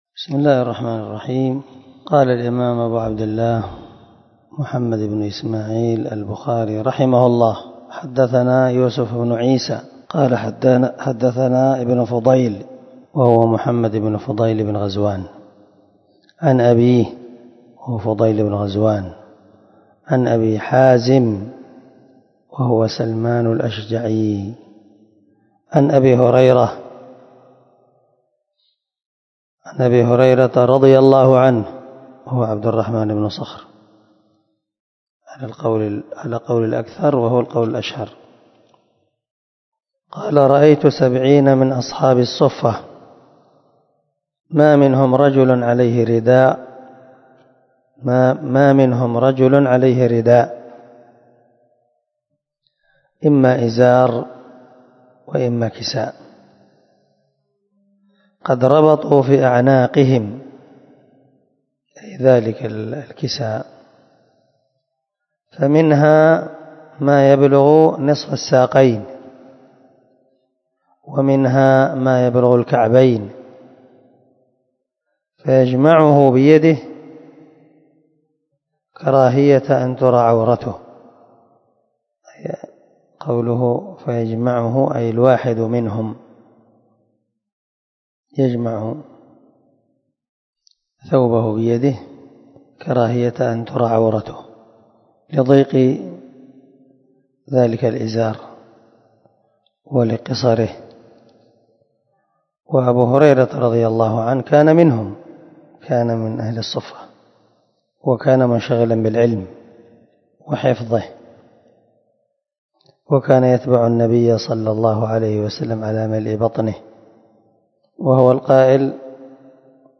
330الدرس 63 من شرح كتاب الصلاة حديث رقم ( 442 – 443 ) من صحيح البخاري
دار الحديث- المَحاوِلة- الصبيحة.